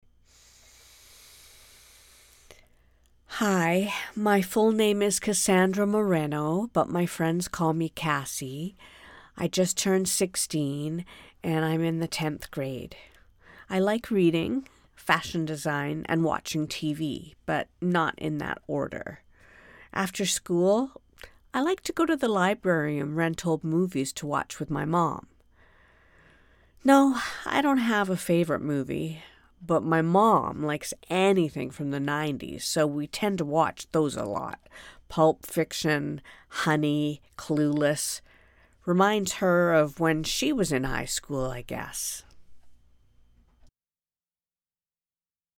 Teenage character
canadian, authentic british dialects